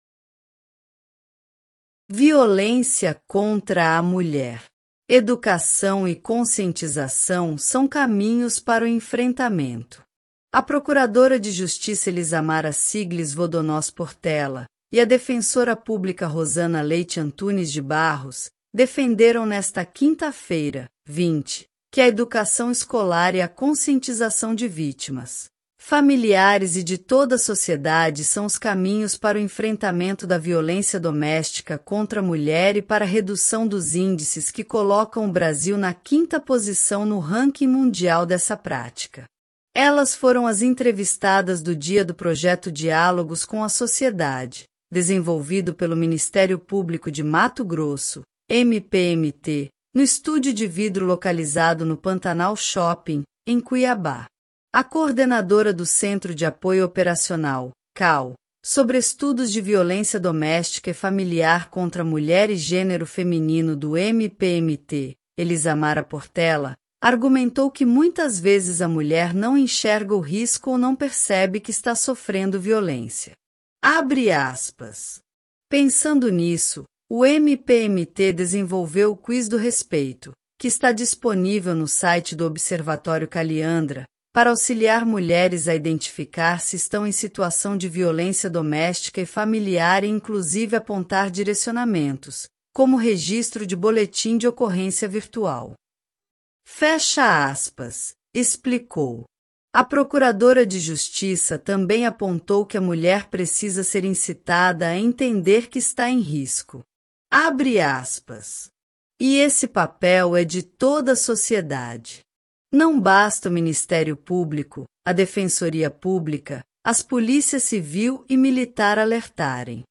A procuradora de Justiça Elisamara Sigles Vodonós Portela e a defensora pública Rosana Leite Antunes de Barros defenderam, nesta quinta-feira (20), que a educação escolar e a conscientização de vítimas, familiares e de toda a sociedade são os caminhos para o enfrentamento da violência doméstica contra a mulher e para a redução dos índices que colocam o Brasil na quinta posição no ranking mundial dessa prática. Elas foram as entrevistadas do dia do projeto Diálogos com a Sociedade, desenvolvido pelo Ministério Público de Mato Grosso (MPMT), no estúdio de vidro localizado no Pantanal Shopping, em Cuiabá.